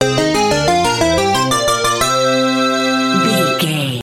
Ionian/Major
bouncy
bright
cheerful/happy
playful
synthesiser